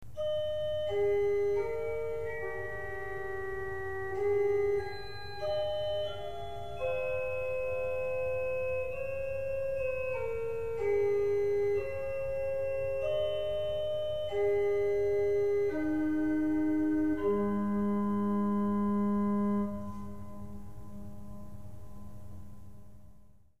Tercja, Terz, Tierce
Nazwa głosu alikwotowego, brzmiącego unisono z jednym z tercjowych alikwotów głosu podstawowego.
tercja_z_8.mp3